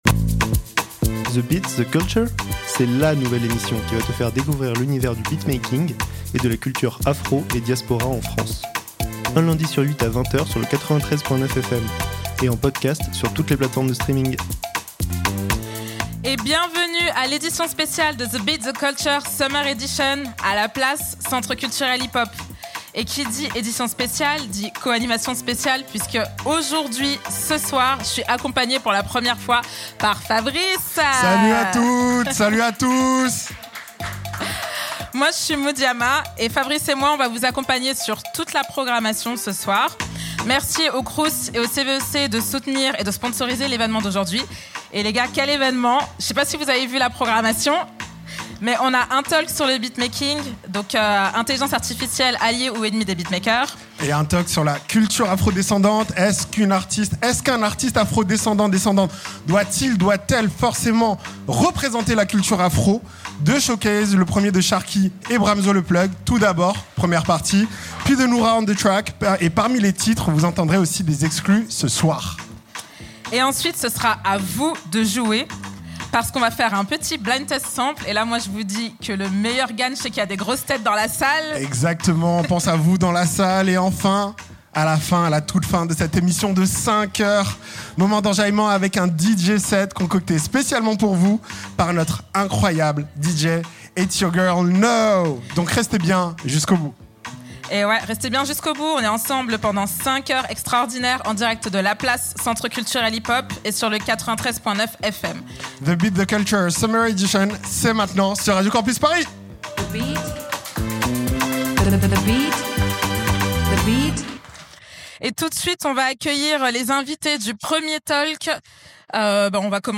The Beat, The Culture fête l'été avec une édition spéciale de 5 heures en direct de La Place, centre culturel hip-hop !